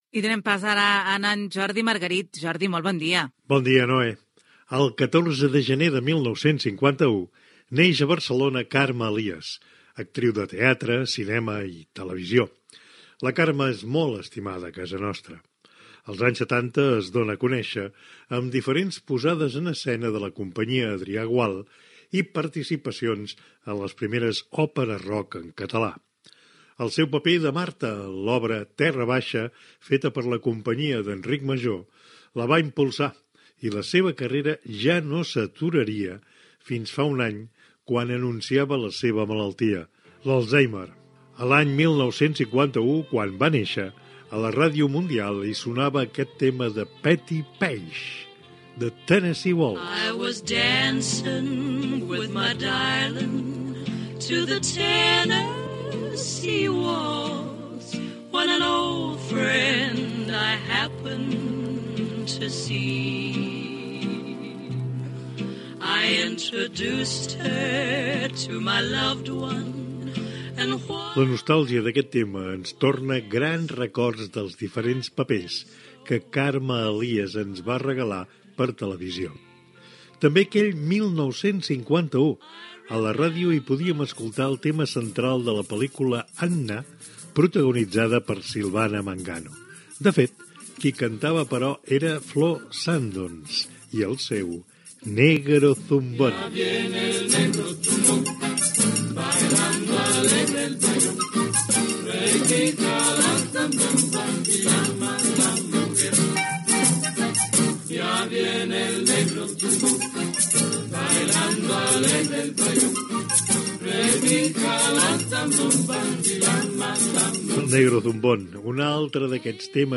Espai "Ja surt el sol" dedicat a l'any 1951 i a l'actriu Carme Elias, amb dades d'aquell any, perfil biogràfic de l'actriu i un fragment de Ràdio Olot extret de l'Arxiu Sonor de la Ràdio a Catalunya de la Universitat Autònoma de Barcelona
Entreteniment